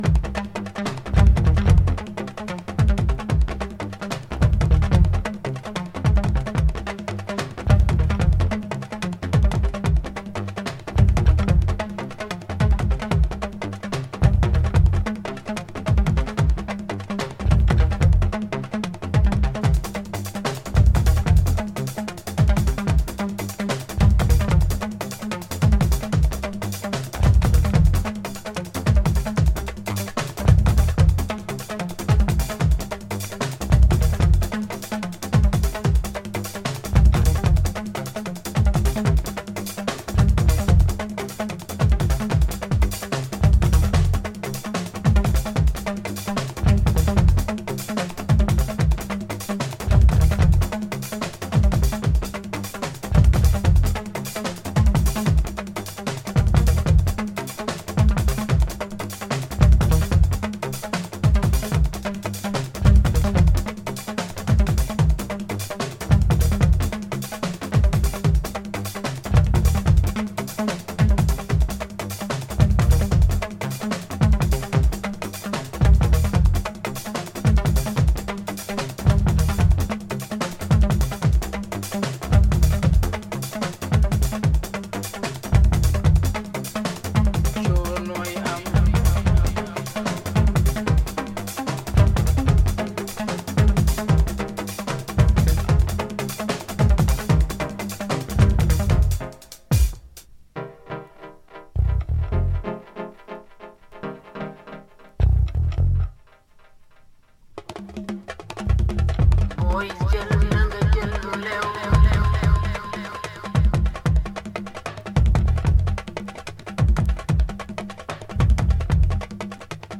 JUNGLE/BREAKBEAT